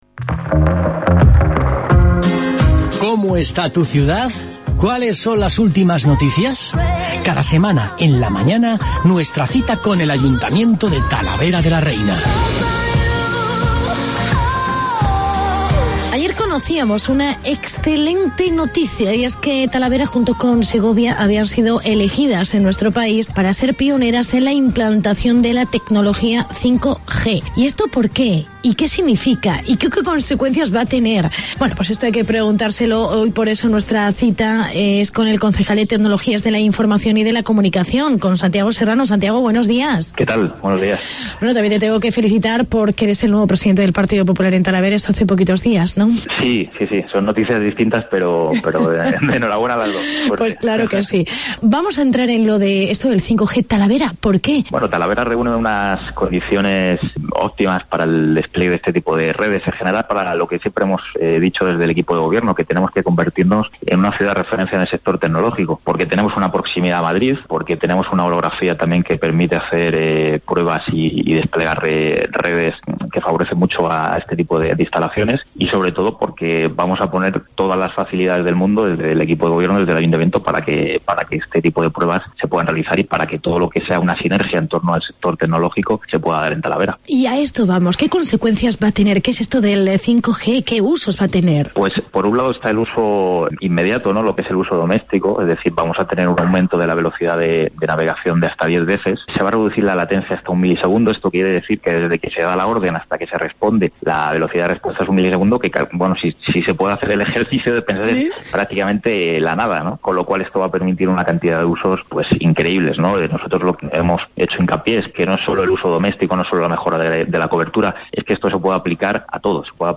Entrevista con el concejal talaverano: Santiago Serrano